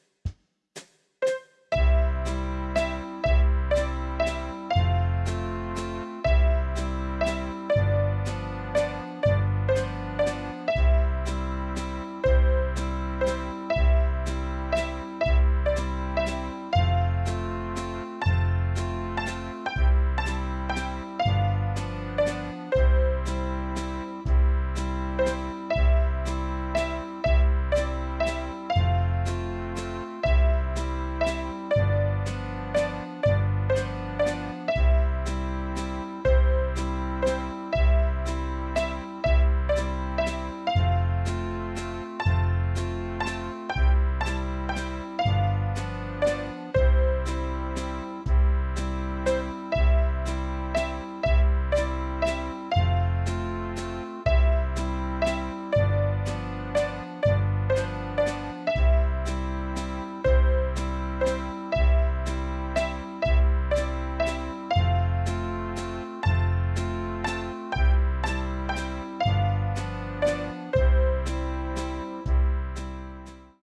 Poco convencido compré un teclado usado y el libro Keyboard Starter 1.